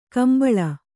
♪ kambaḷa